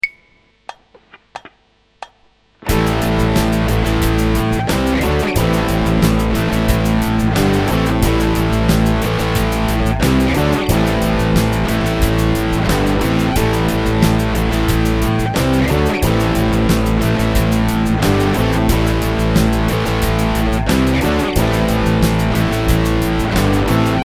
Example #5 simply uses power chords to create accents similar to what you would hear in a typical AC DC or The Who song.